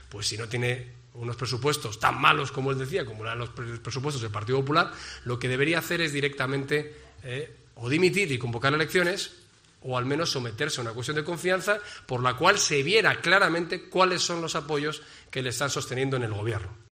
Así lo ha comentado Casado desde Jaén, en el transcurso de su intervención en los desayunos informativos de Europa Press Andalucía organizados en colaboración con Fundación Cajasol, Orange y laboratorios VIR, donde ha presentado al presidente del PP-A, Juanma Moreno, y ha aludido a las "advertencias" que la Comisión Europea ha mandado a España "sobre el Presupuesto presentado" por el Gobierno socialista, "diciendo que no se lo creía".